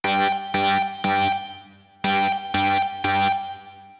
Alarm 2 Loop.wav